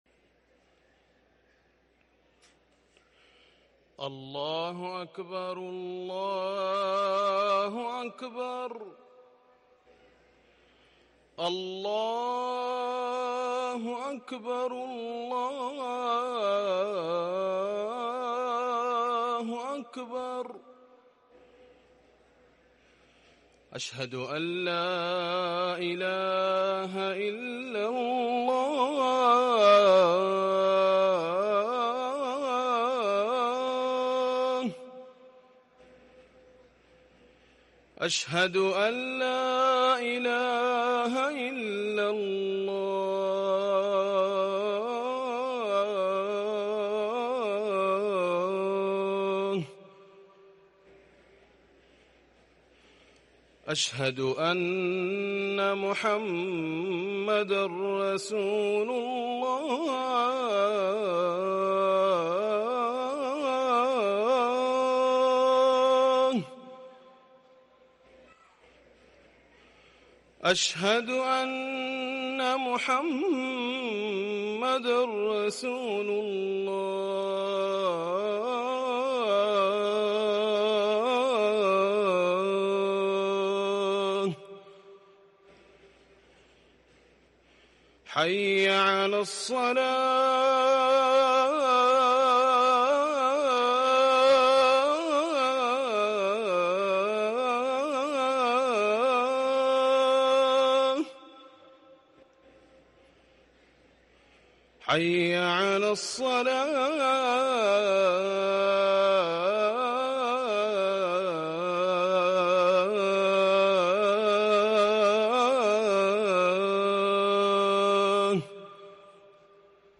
اذان العشاء